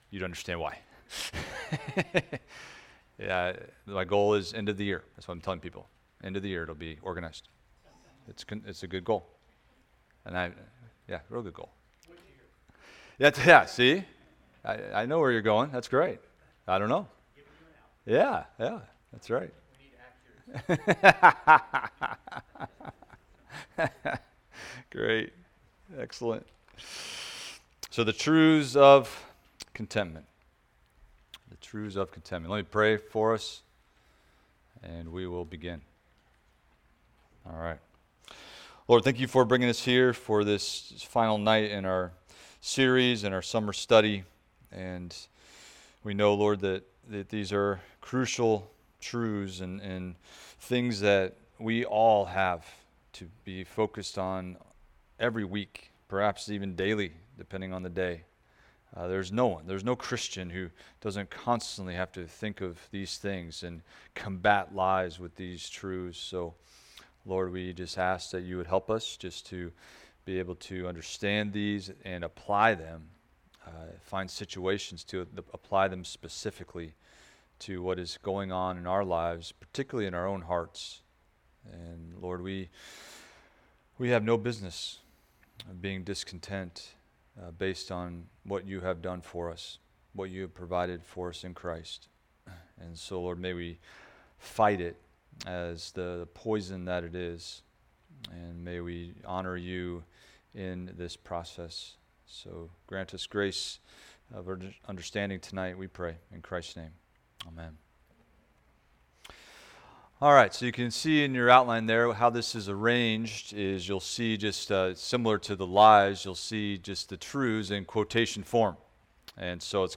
Expository Preaching on Contentment (Session 3)
Summer Sunday Evening series on Contentment.